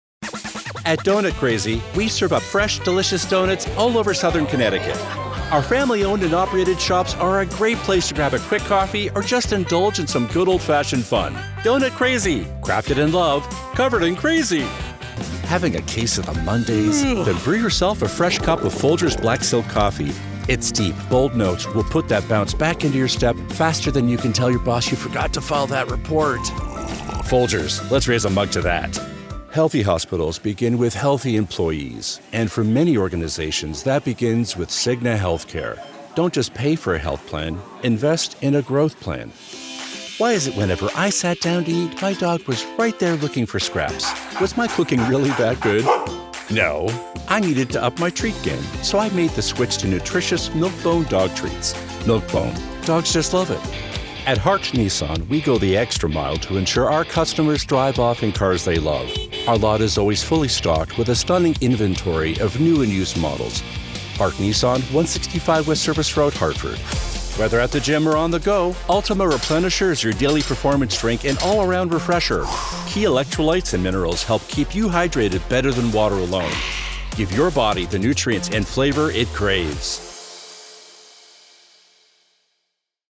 voiceover, vo, narration, voice actor, home studio